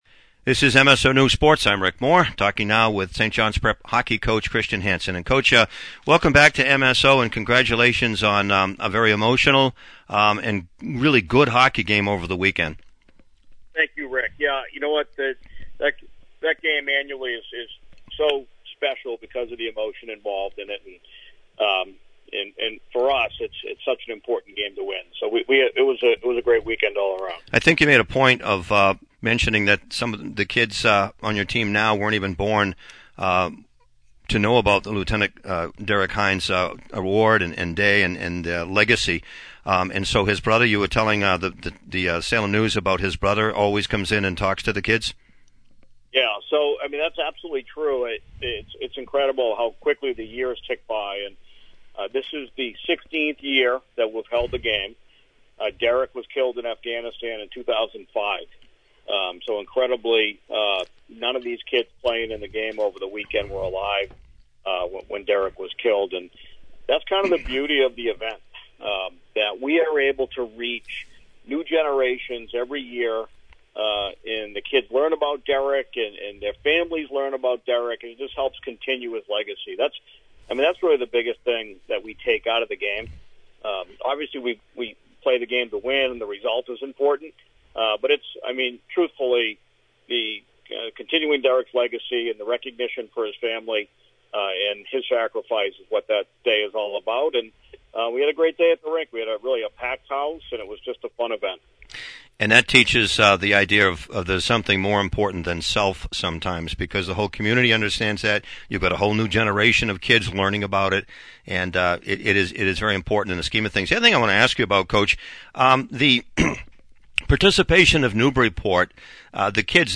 In the interview below